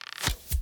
Bow Attacks Hits and Blocks
Bow Attack 2.wav